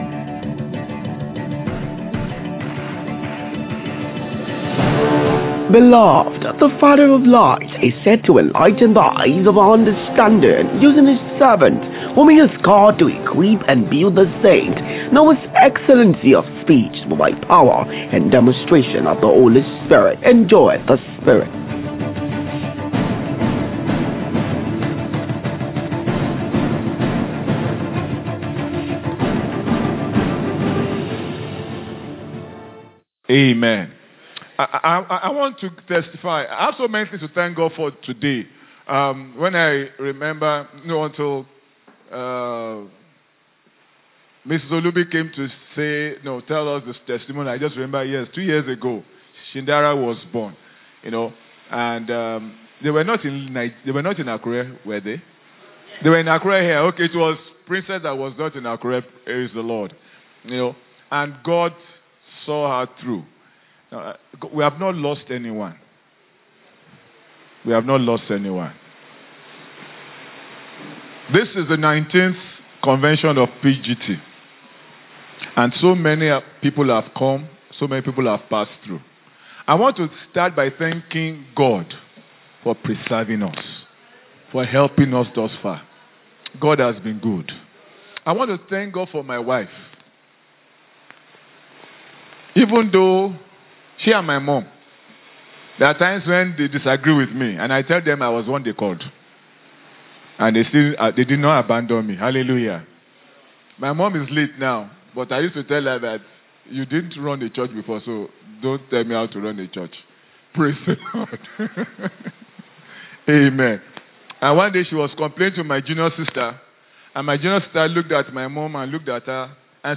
The Power of the Kingdom Day 5 (Sunday Service: Ordination) – Power & Glory Tabernacle
Sunday-Service-Ordination.mp3